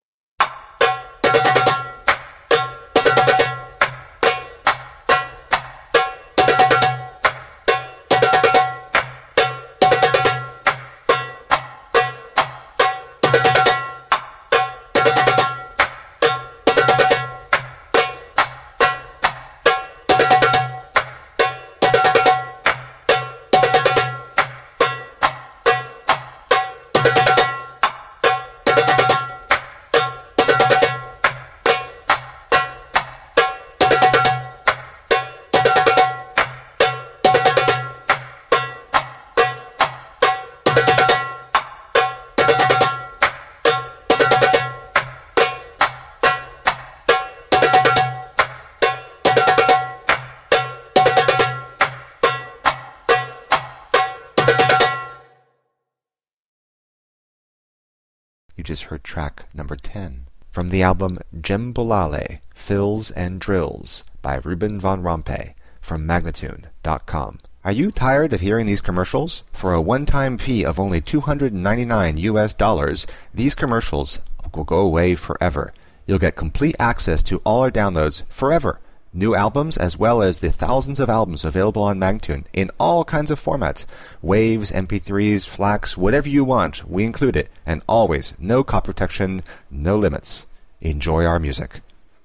Passionate eastern percussion.